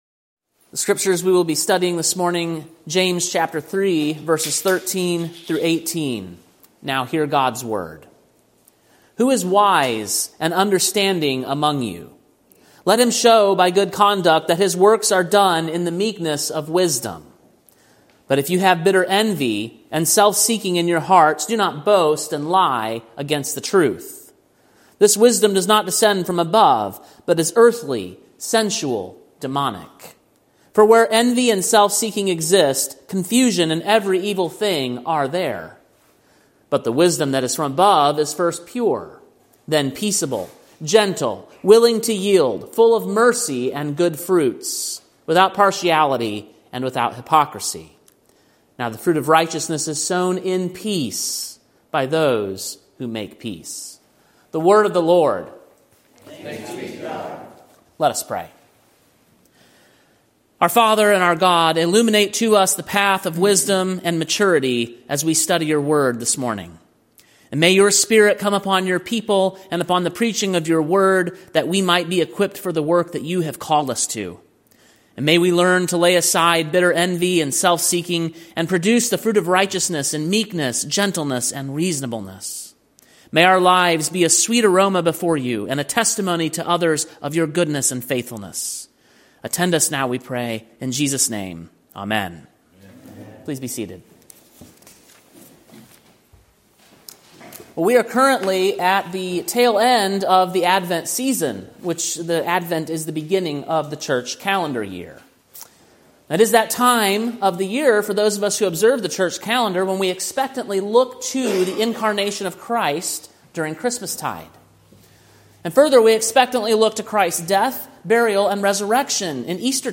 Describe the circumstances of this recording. Sermon preached on December 22, 2024, at King’s Cross Reformed, Columbia, TN.